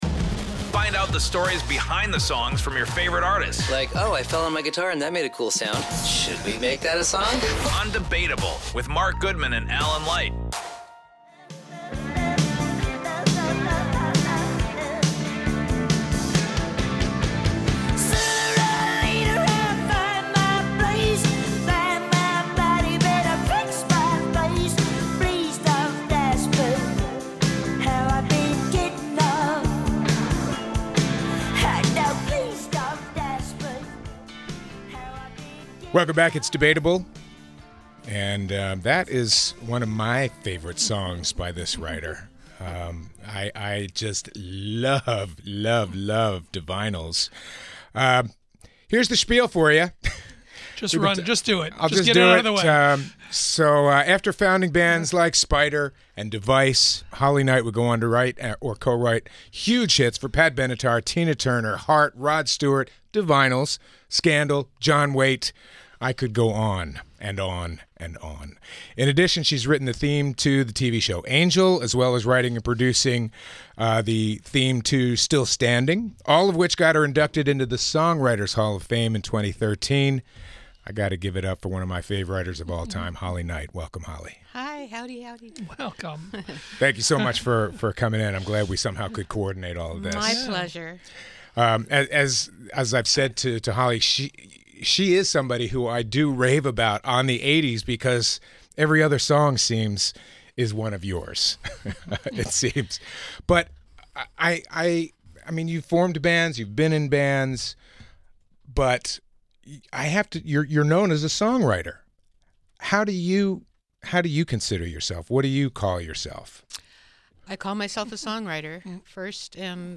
Recent Radio & Podcast Interviews: